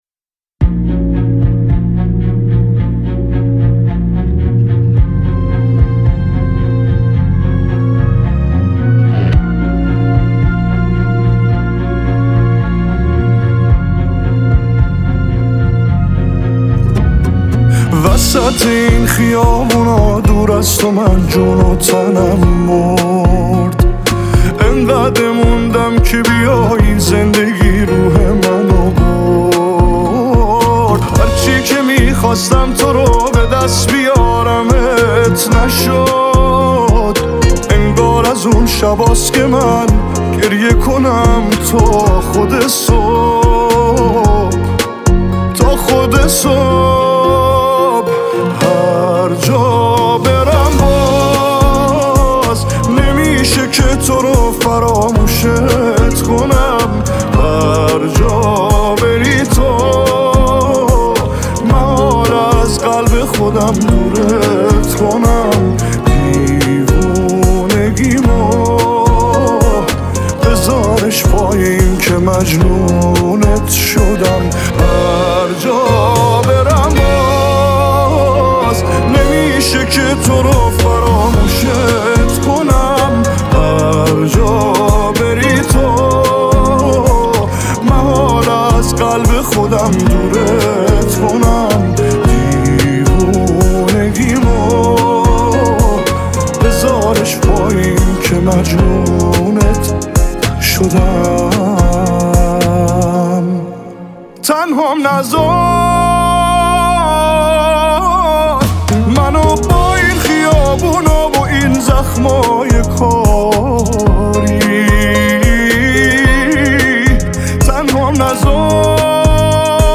گیتار الکتریک‌
سبک : پاپ
احساسات : دلپذیر ، غمگین انرژی : میانه